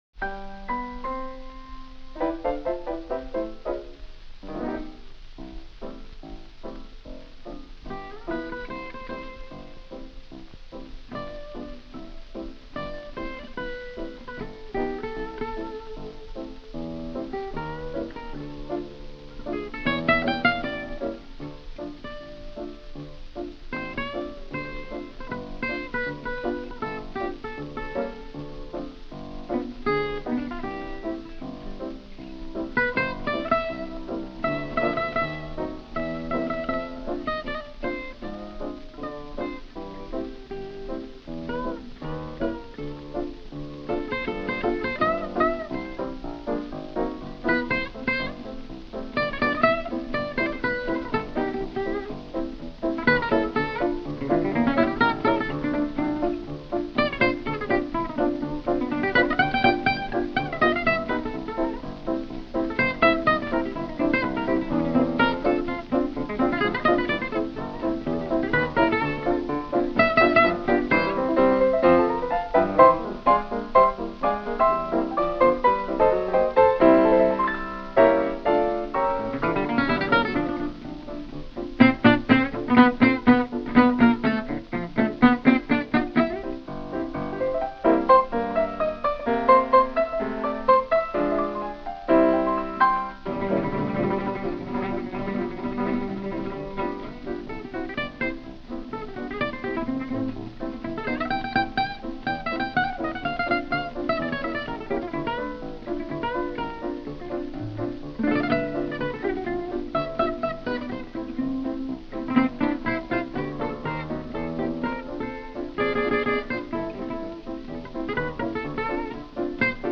Versione strumentale